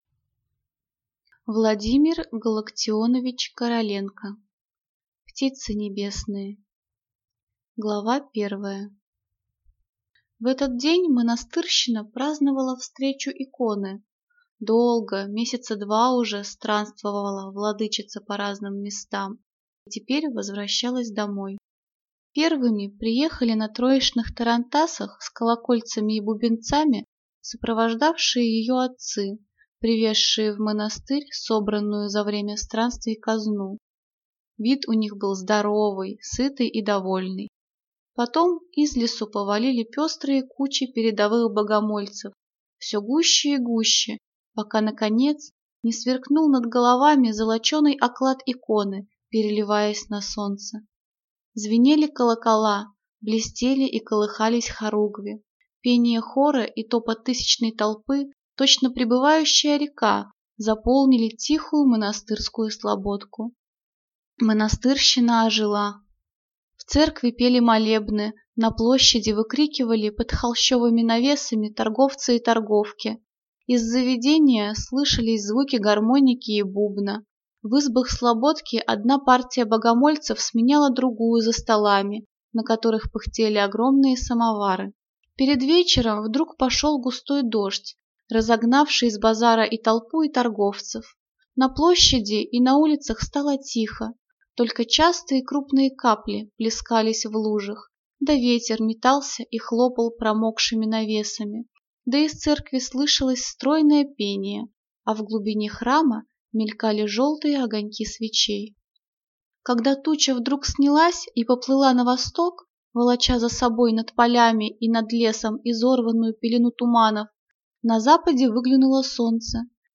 Аудиокнига Птицы небесные | Библиотека аудиокниг